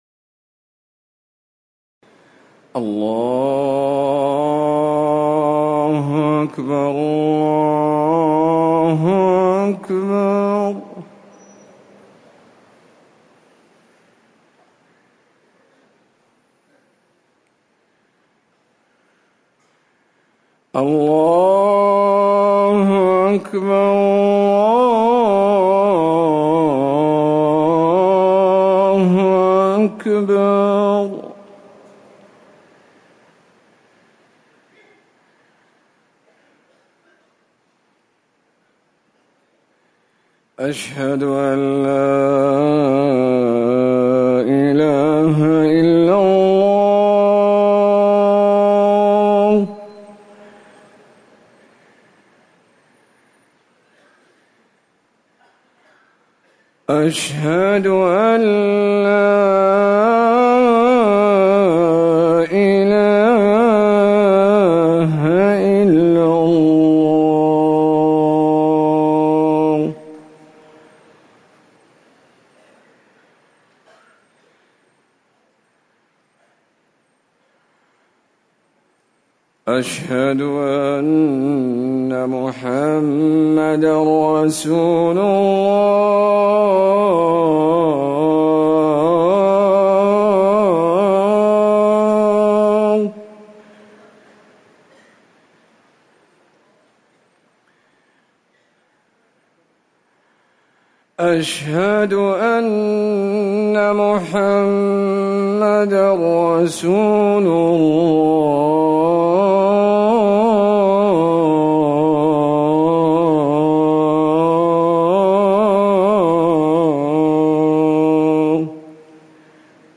أذان الفجر الأول
تاريخ النشر ٢٣ صفر ١٤٤١ هـ المكان: المسجد النبوي الشيخ